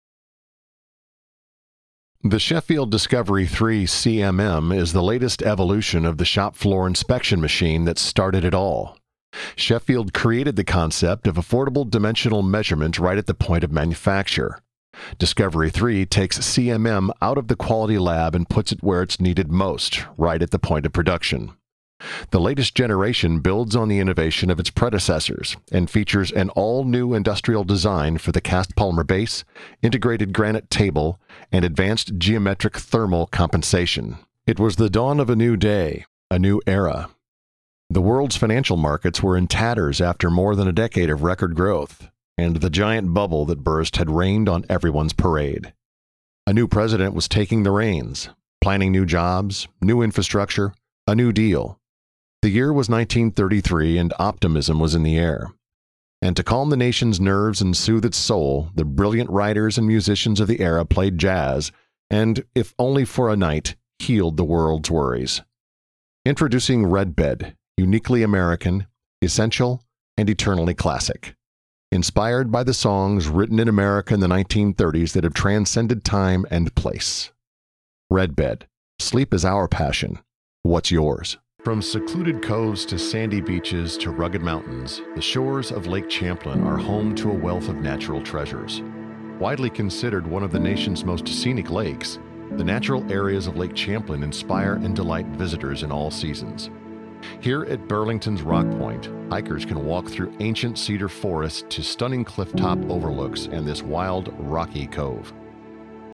Male Demo 2